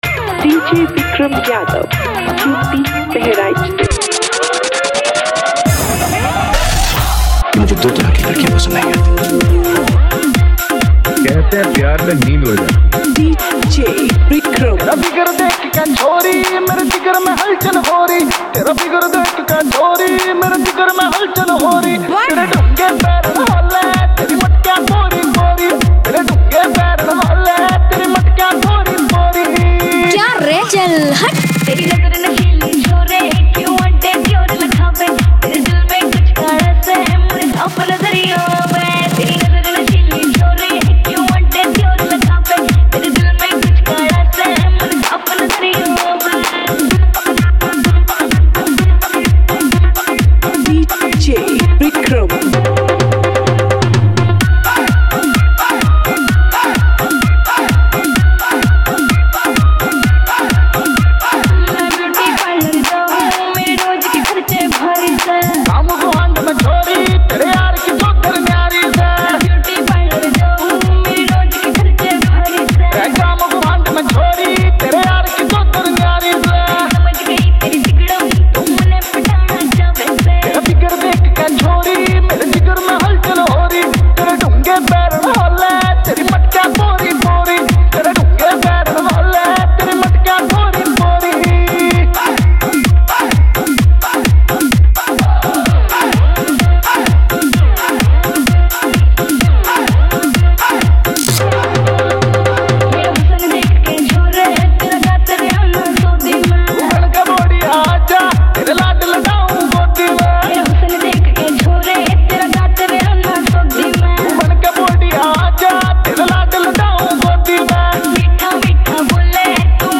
[ DJ Remix Songs ]